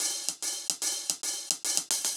Index of /musicradar/ultimate-hihat-samples/110bpm
UHH_AcoustiHatA_110-02.wav